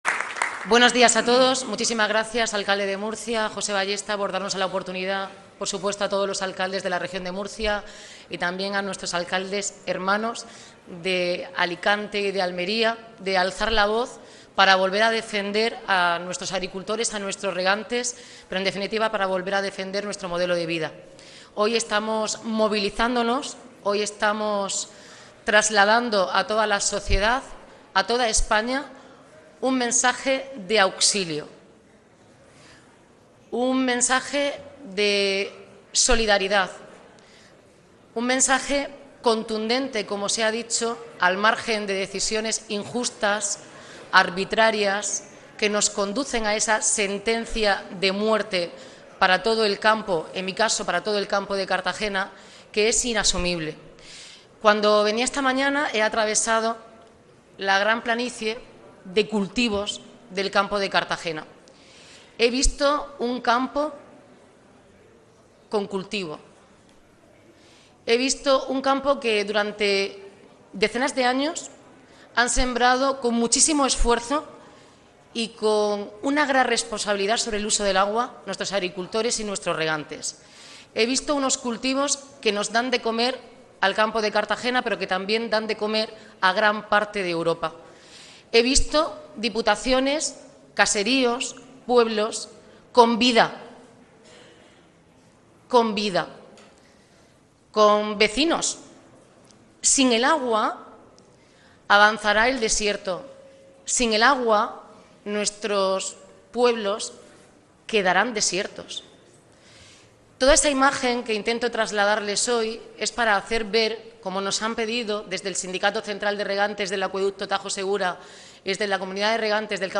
Arroyo ha llamado a la movilización y ha explicado que el campo de Cartagena es el área más amenazada de toda la zona regable de Levante durante el acto institucional de Alcaldes por el Trasvase Tajo-Segura, celebrado este jueves 5 de junio en el Cuartel de Artillería de Murcia, que ha contado con la asistencia de cerca de 91 representantes de los municipios de la Región, pero también de las provincias vecinas de Alicante y Almería.